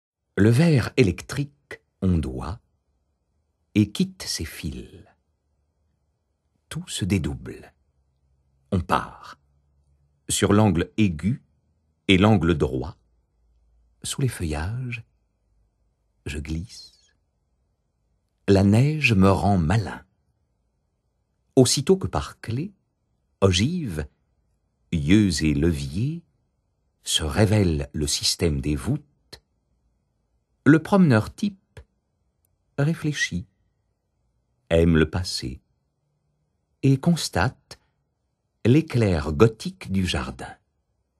je découvre un extrait - Le citadin de Odilon-Jean Périer